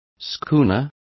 Complete with pronunciation of the translation of schooners.